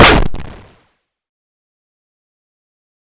failure.wav